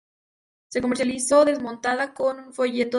mon‧ta‧je
/monˈtaxe/